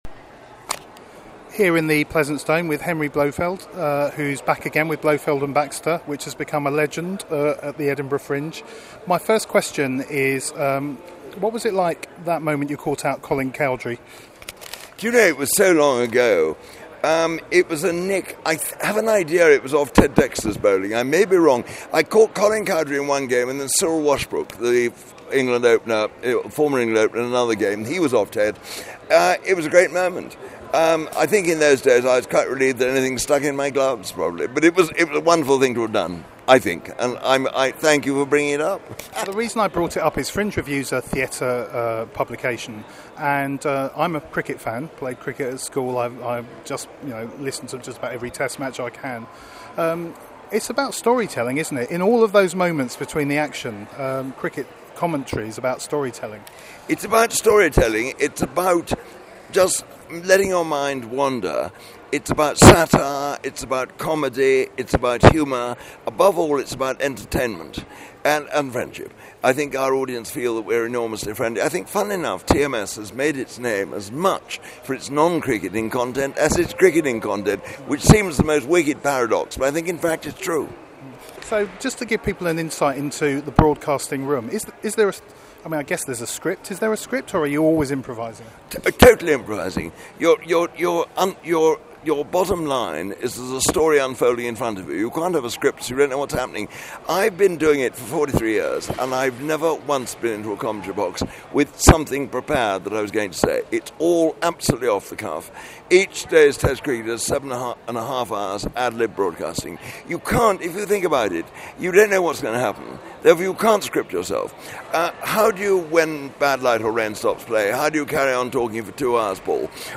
listen-to-our-interview-with-henry-blofeld.mp3